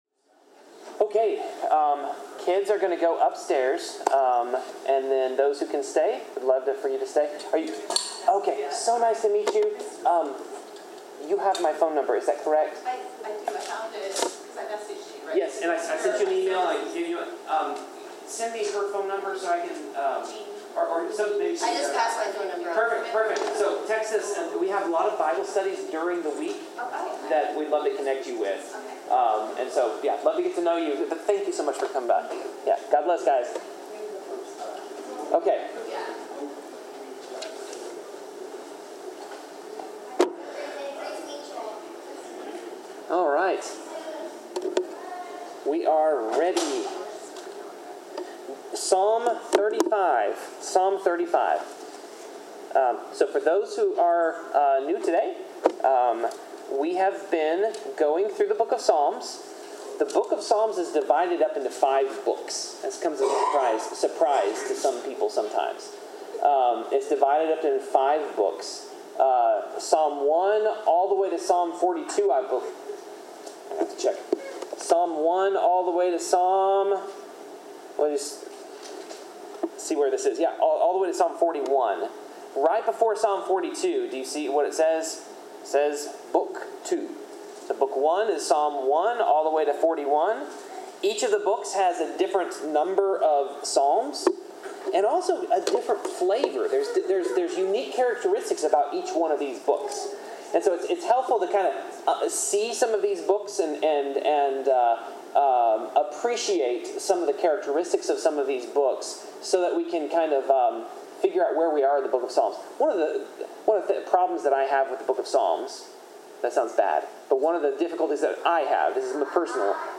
Bible class: Psalms 35-36
Passage: Psalms 35-36 Service Type: Bible Class